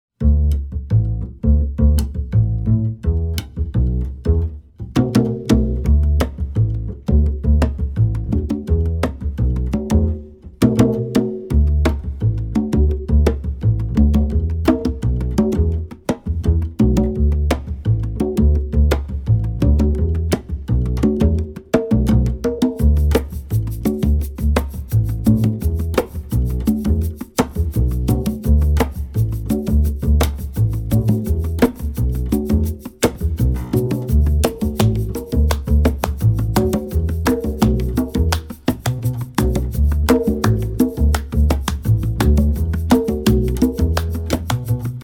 Listen to a sample of the instrumental track.